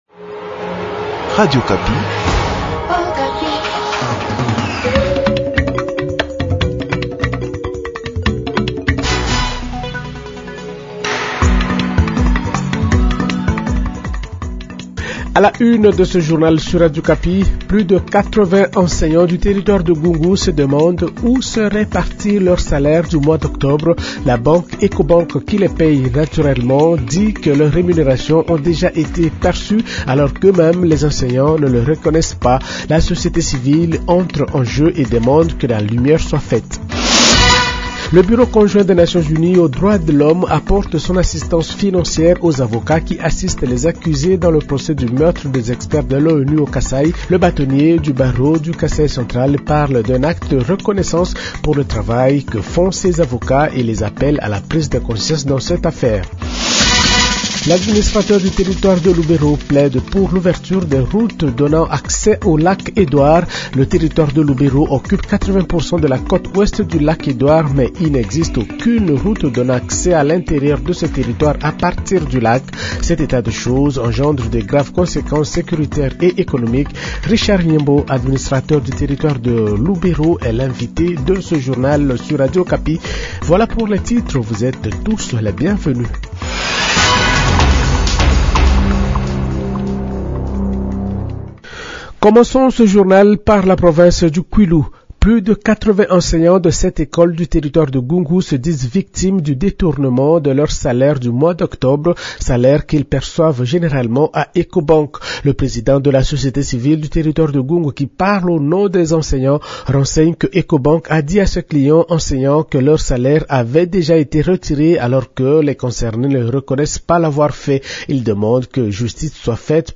Journal Francais matin 8h00